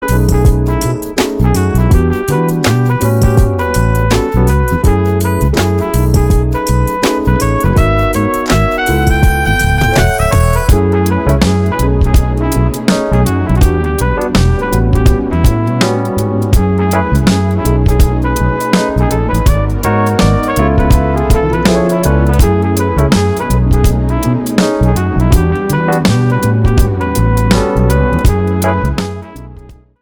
EASY LISTENING  (02.16)